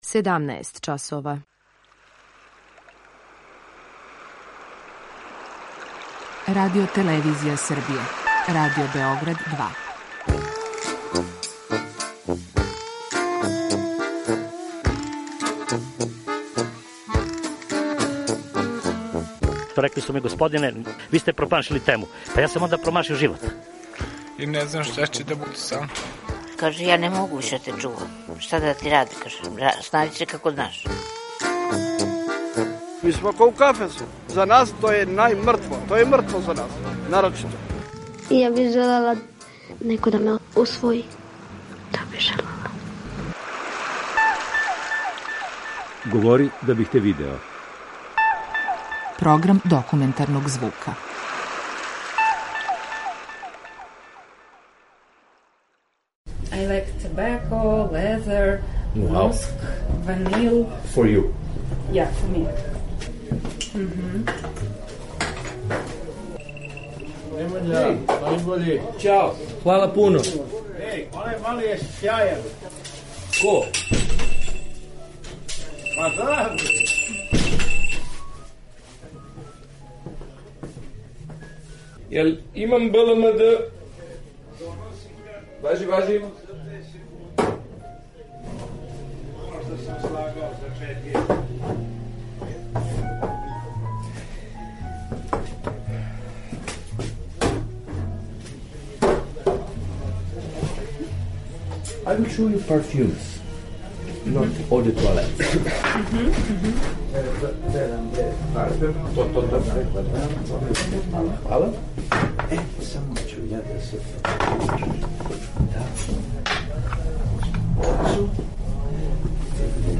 Документарни програм
Серија полусатних документарних репортажа, за чији је скупни назив узета позната Сократова изрека: "Говори да бих те видео". Ова оригинална продукција Радио Београда 2 сједињује квалитете актуелног друштвеног ангажмана и култивисане радиофонске обраде.